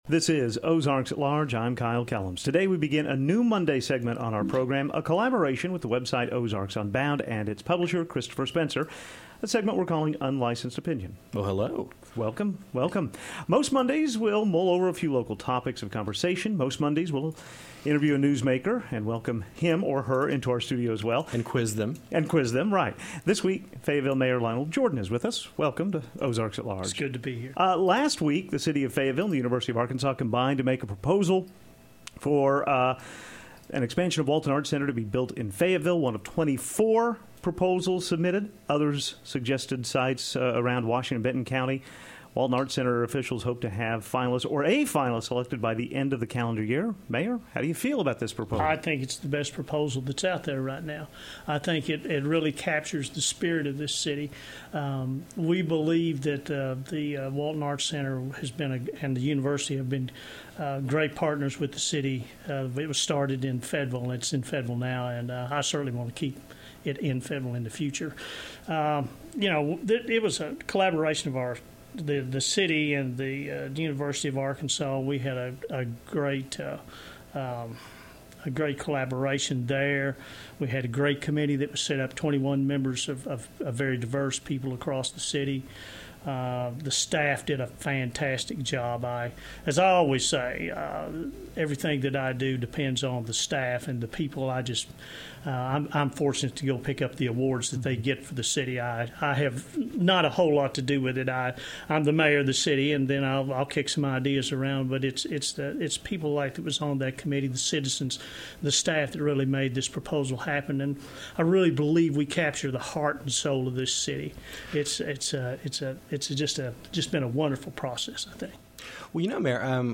This week the talked with the mayor of Fayetteville, Lioneld Jordan.